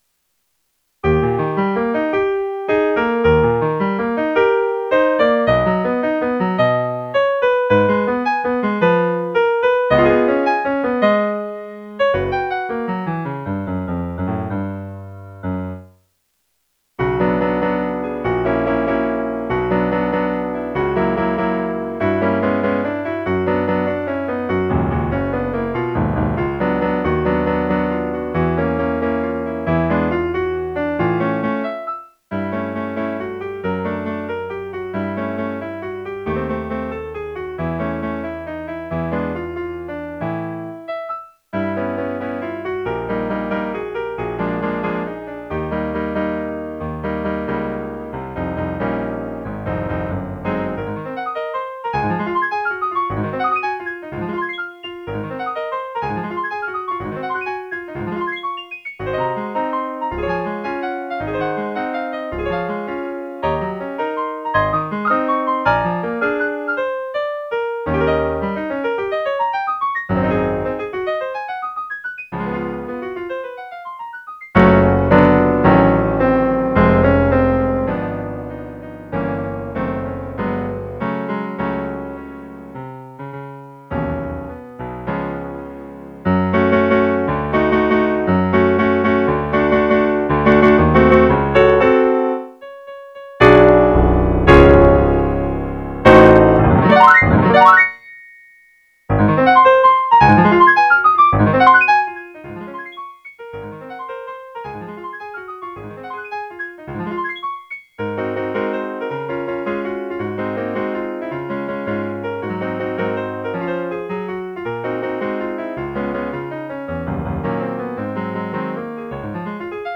''Kennst du die Rosen'' - 피아노 반주